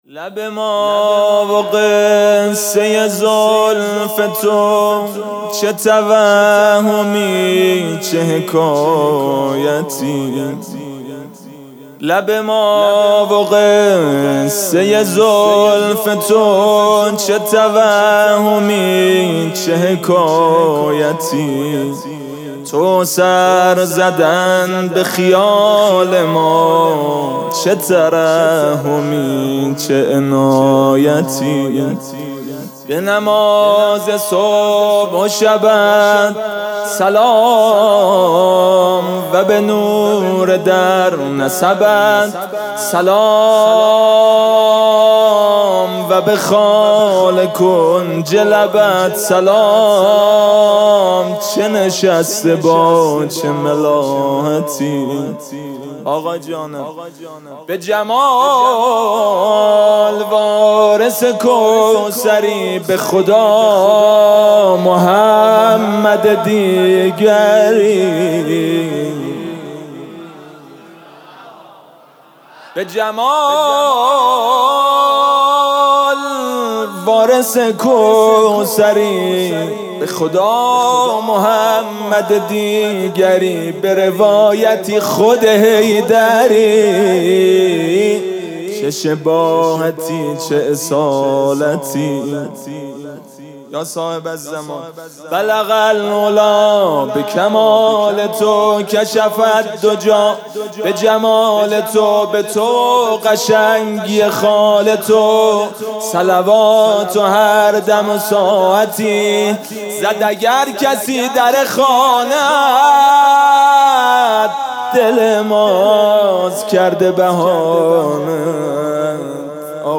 جلسه هفتگی
جشن نیمه شعبان
music-icon مدح: لب ما و قصه زلف تو....